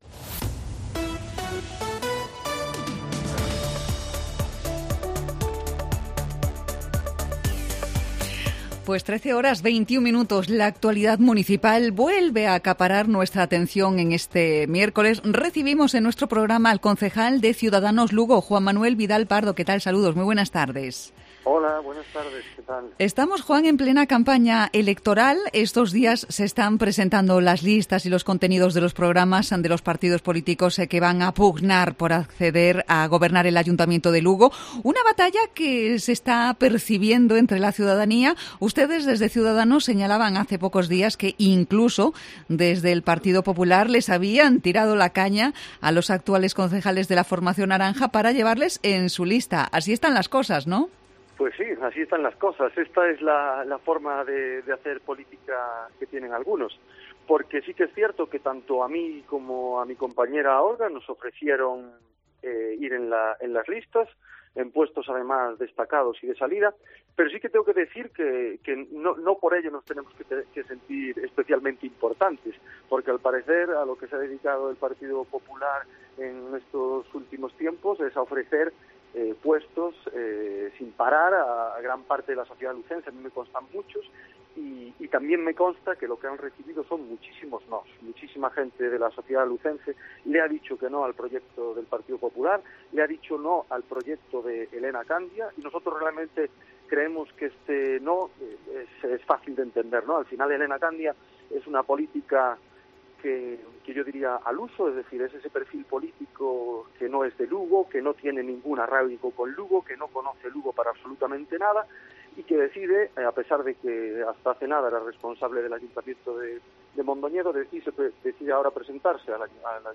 Entrevista a Ciudadanos Lugo en Cope Lugo